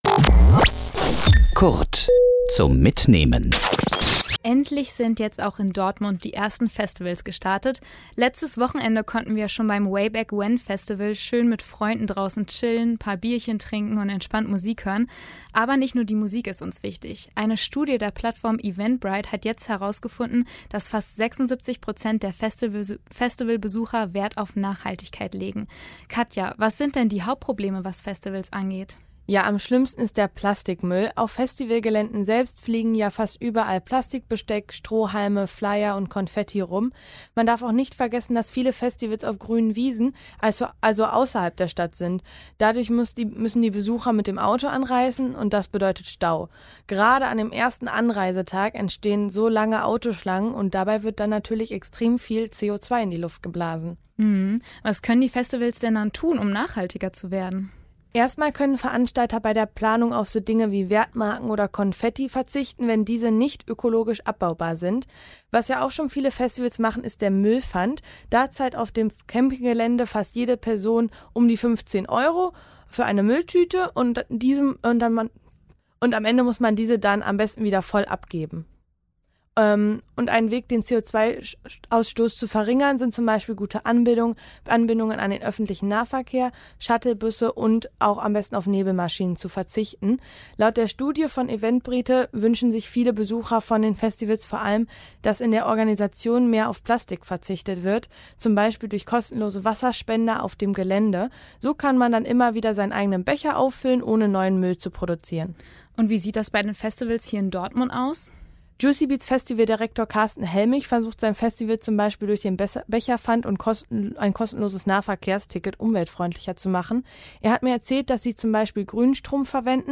Kollegengespräch  Sendung